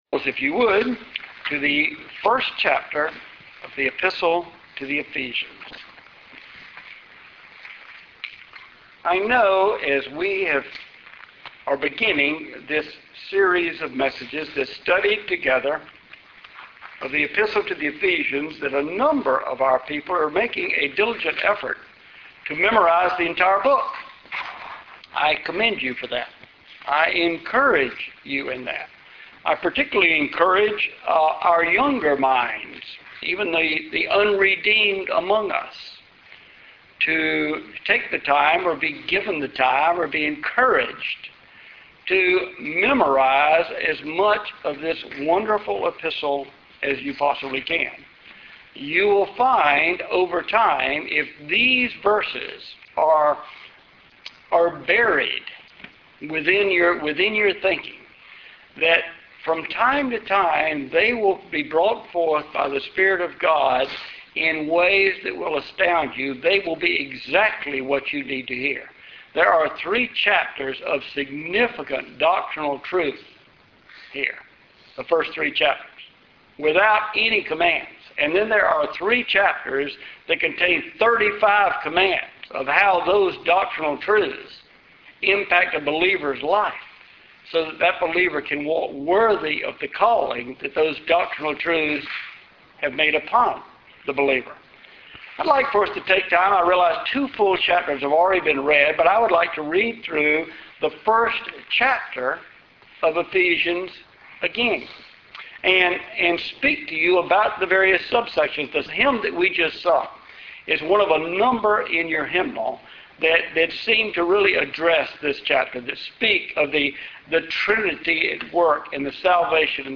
Passage: Ephesians 1:3-6 Service Type: Sunday Morning